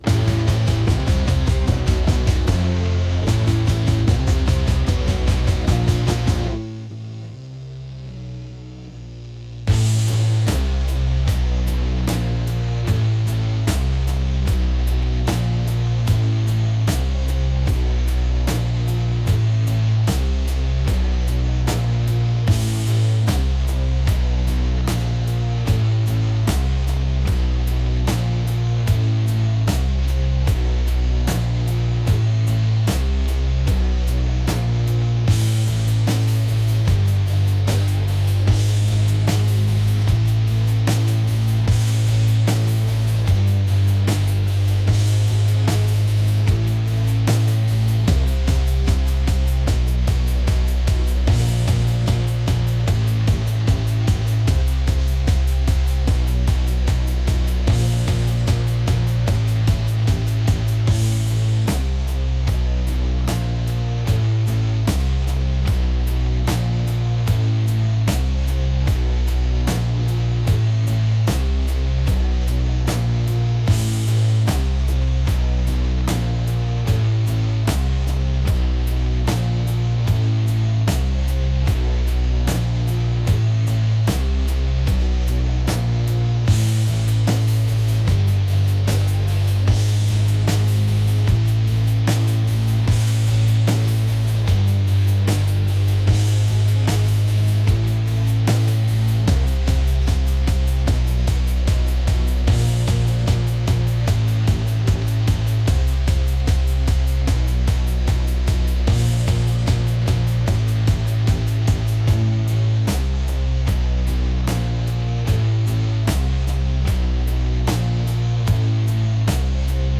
energetic | rock